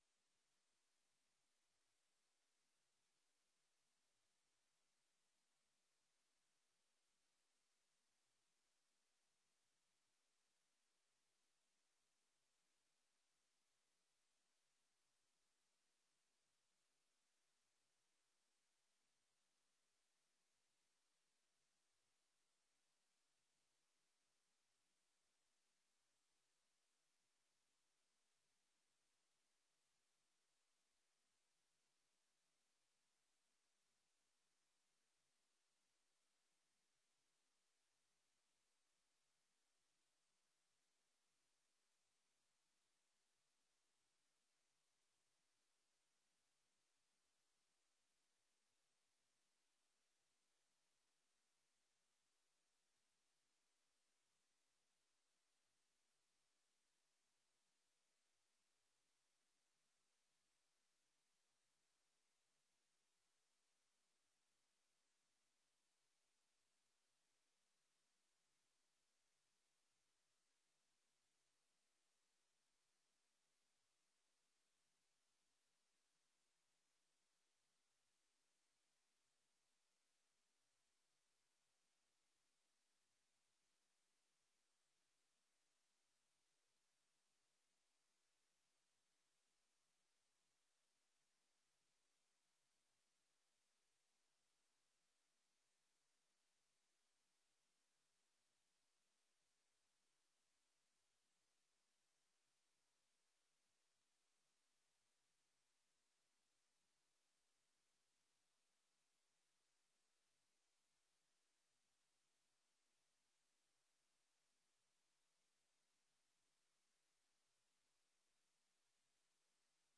Download de volledige audio van deze vergadering
De bijeenkomst is hybride met de voorkeur kom fysiek aanwezig te zijn.